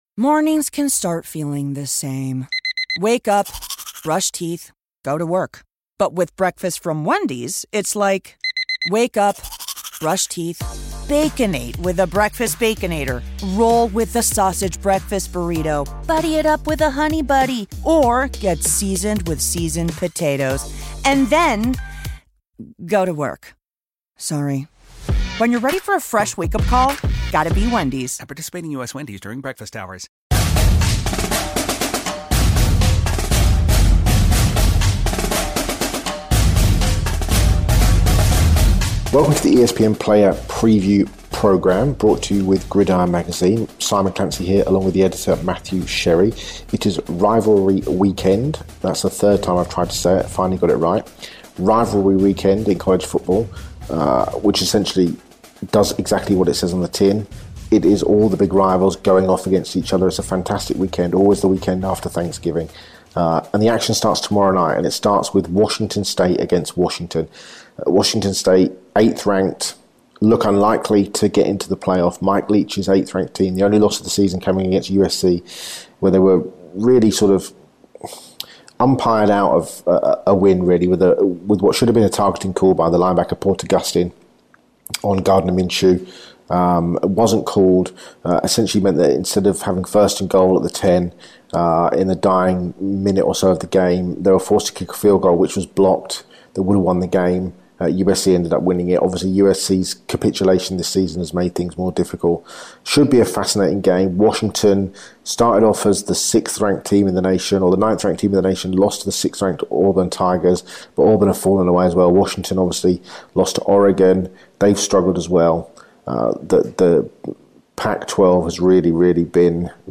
Plus, exclusive interviews with two potential first-round picks, Rashan Gary and Chase Winovich.